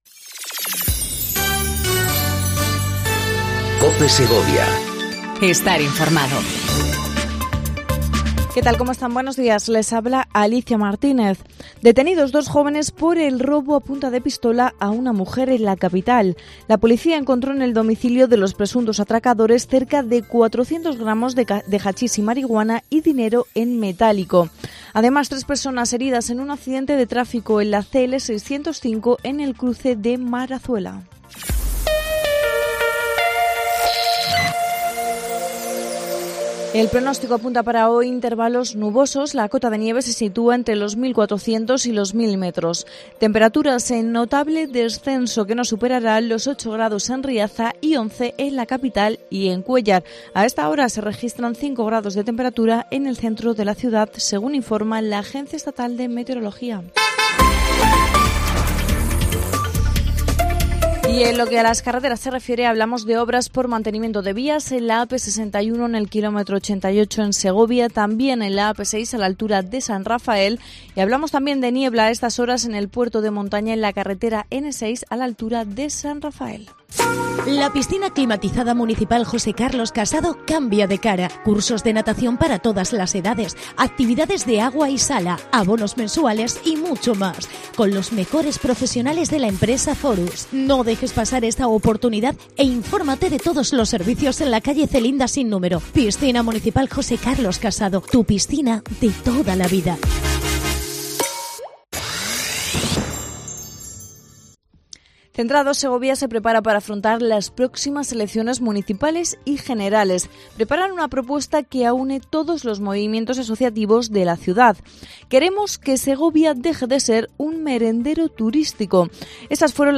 INFORMATIVO 08:25 COPE SEGOVIA 13/03/19
AUDIO: Segundo informativo local en cope segovia